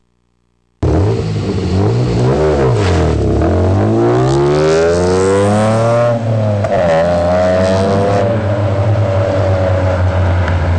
Slow first and second gear accel